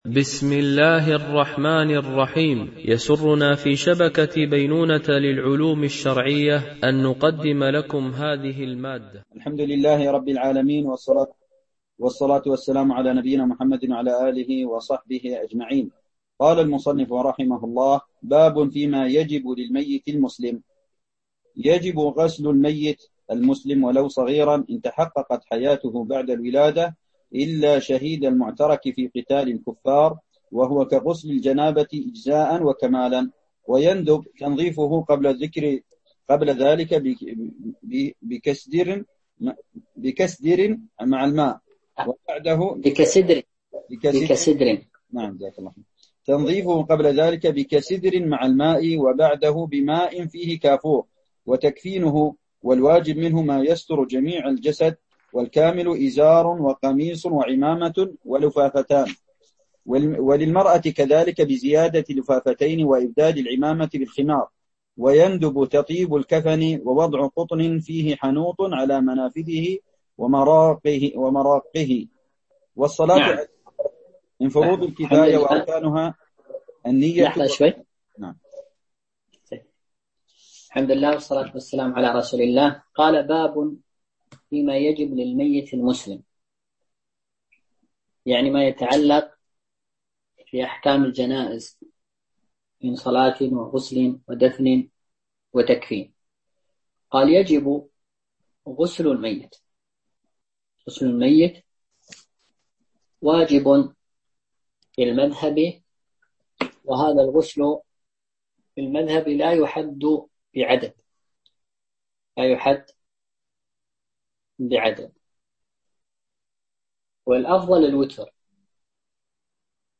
شرح الفقه المالكي ( تدريب السالك إلى أقرب المسالك) - الدرس 27 ( كتاب الصلاة )